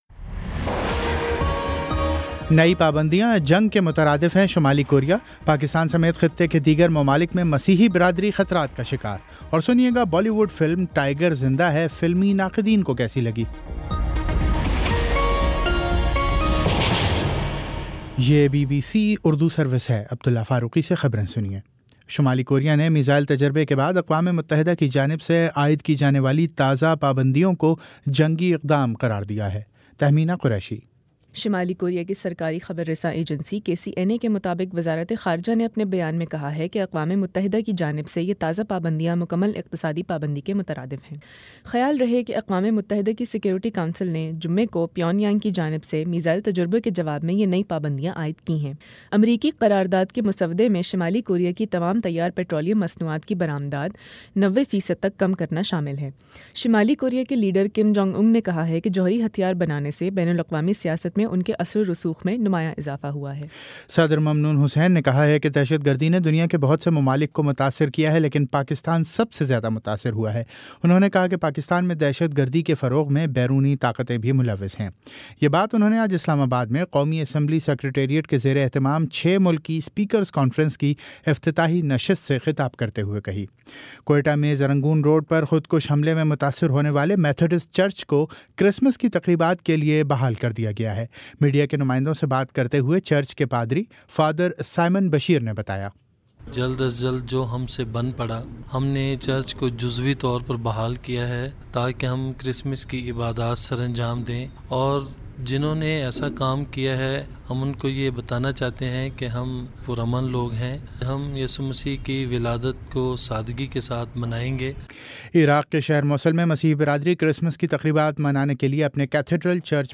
دسمبر 24 : شام پانچ بجے کا نیوز بُلیٹن